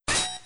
ice_crack.wav